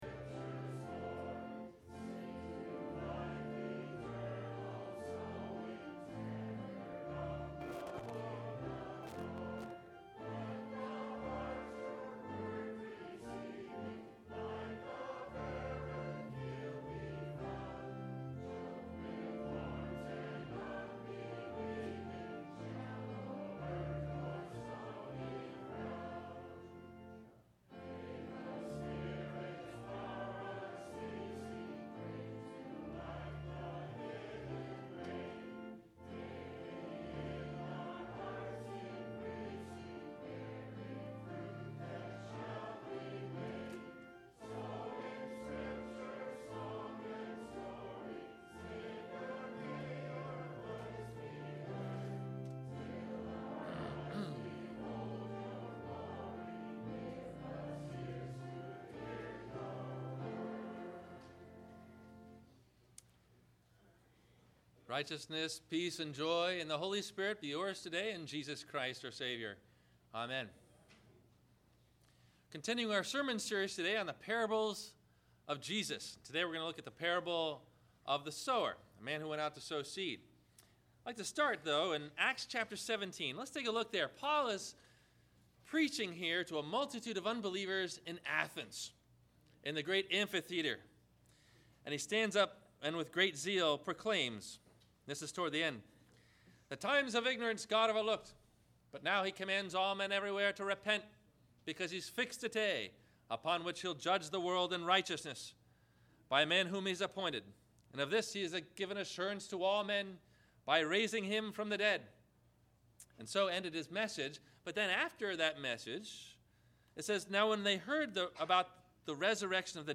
Four Responses to the Gospel Parable of the Sower – Sermon – March 03 2013 - Christ Lutheran Cape Canaveral